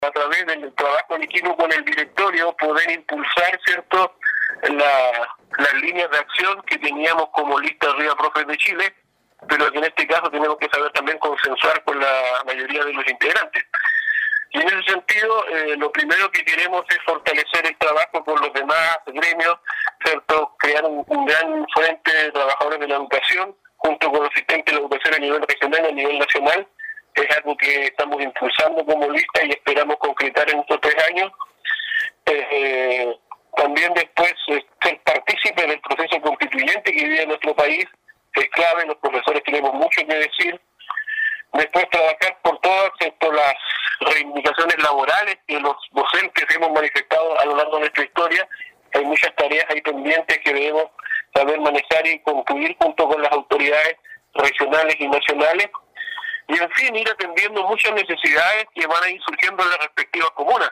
En diálogo con radio Estrella del Mar, sostuvo que espera asumir el cargo conociendo en primera instancia la situación de la institución y del estado de cada una de las comunas que componen esta importante entidad.